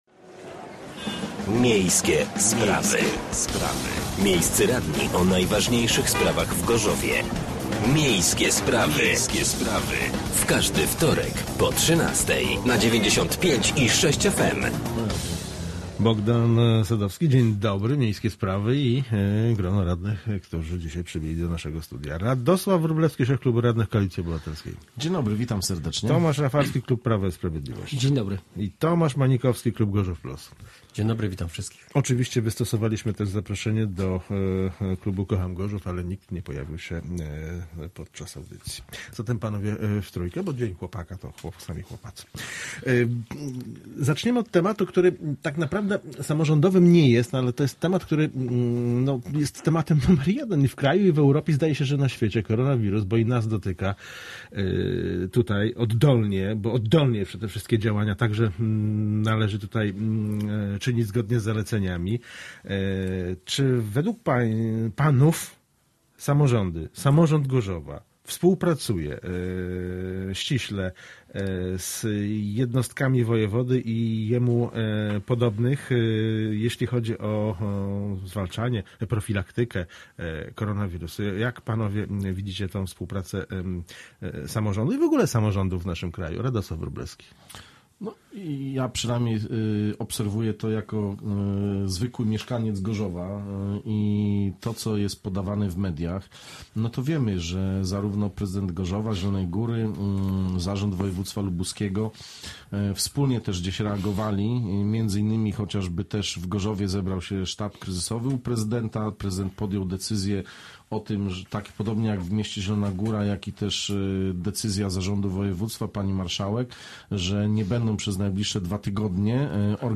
Gośćmi byli radni: Tomasz Manikowski (Gorzów Plus) , Radosław Wróblewski (Koalicja Obywatelska) oraz Tomasz Rafalski (Prawo i Sprawiedliwość)